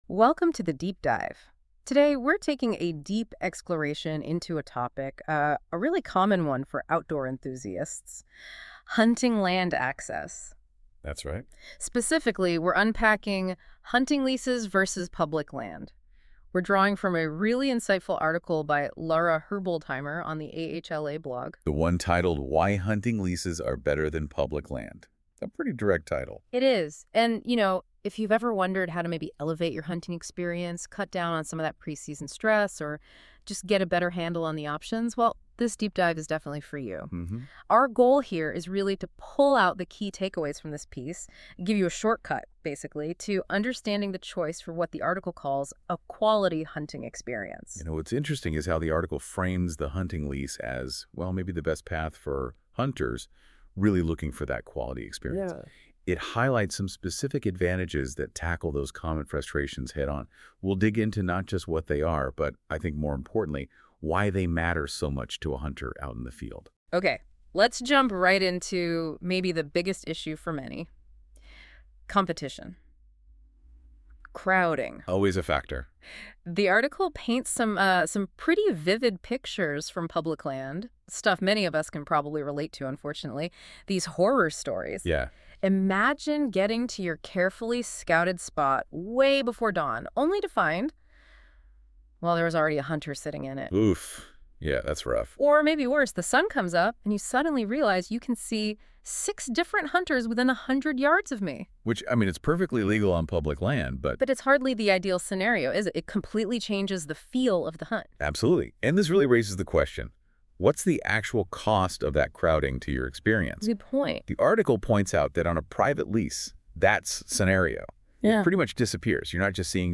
The speakers explore numerous benefits of leasing land, including significantly less competition and crowding, which reduces stress and improves safety, particularly for tactics like still hunting. A major point of discussion is the ability to implement Quality Deer Management (QDM) on a lease, allowing hunters to manage the herd for healthier, more mature animals.